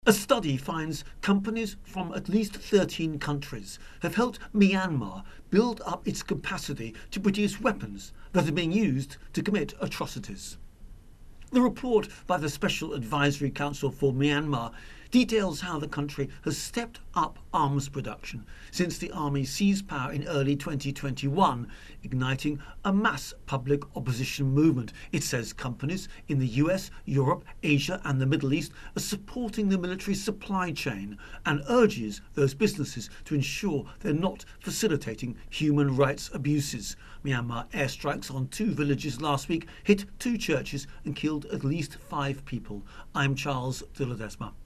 Report: Myanmar arms industry growing after army takeover